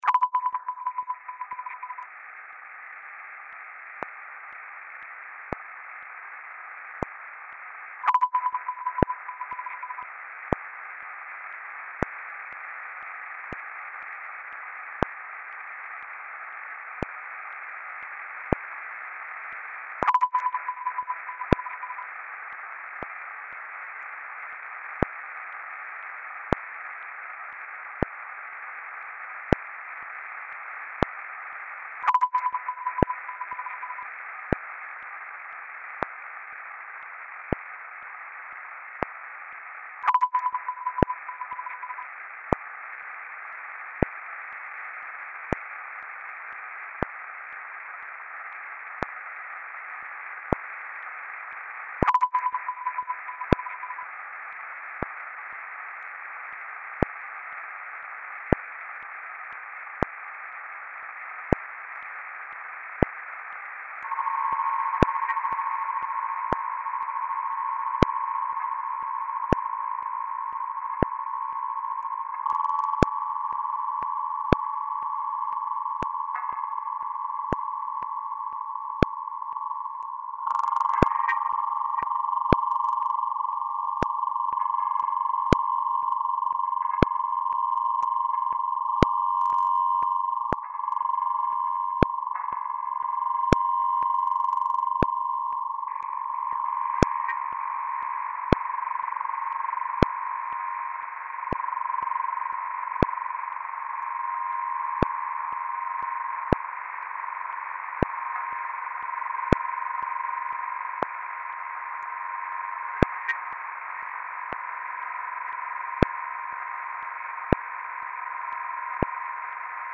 dance/electronic
Ambient